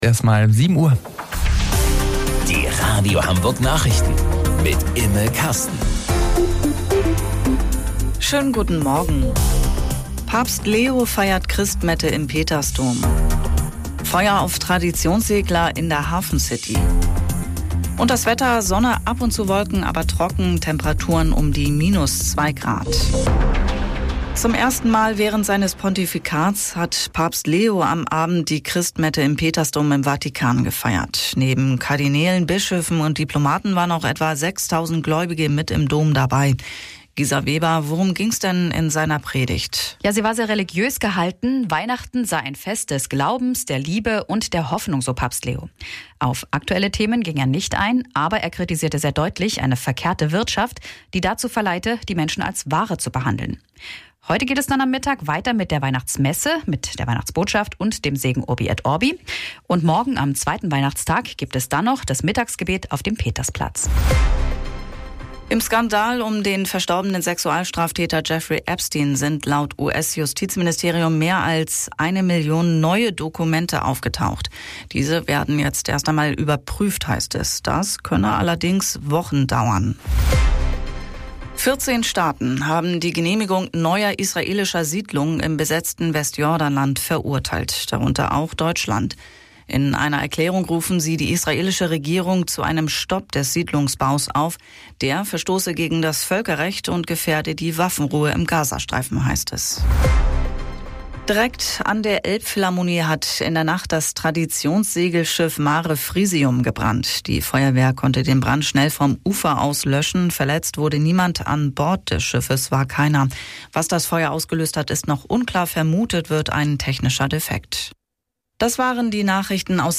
Radio Hamburg Nachrichten vom 25.12.2025 um 07 Uhr